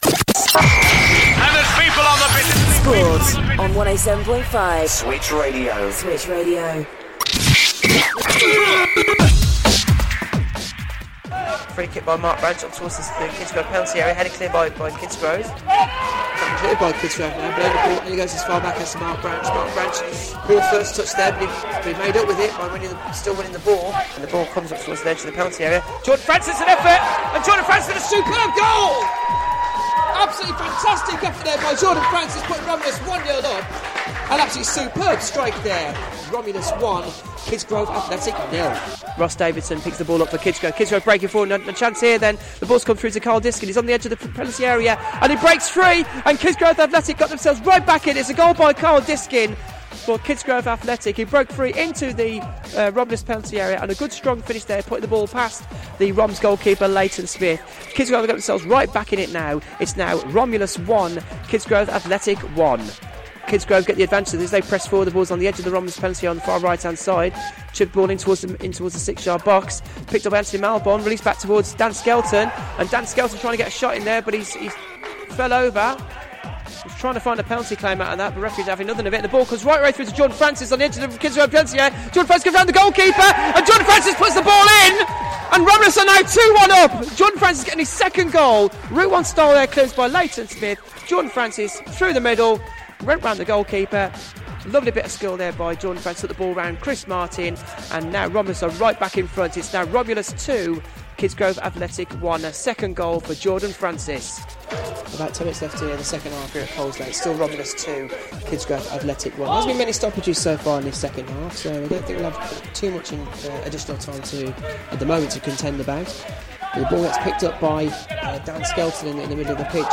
Match commentary highlights and post match reaction